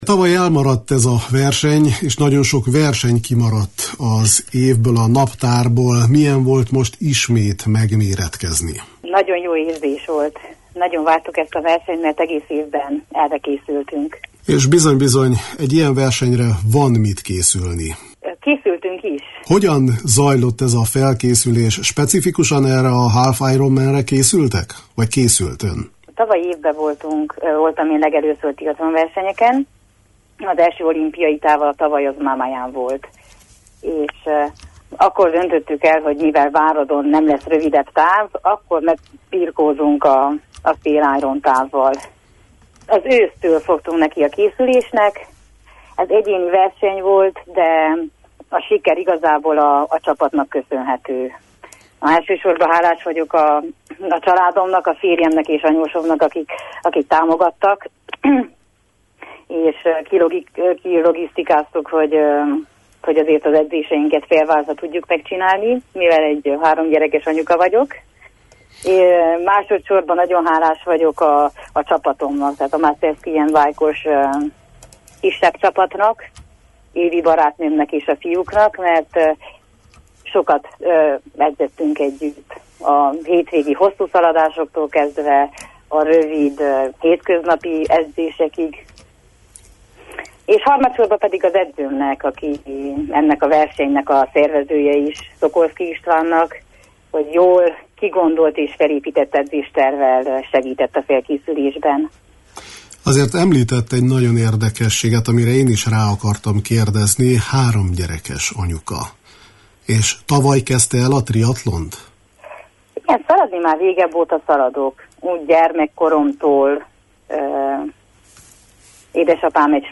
a Kispadon beszélgettünk a felkészüléshez hozzátartozó otthoni logisztikáról, a táplálkozásról, a verseny alatti nehéz pillanatokról: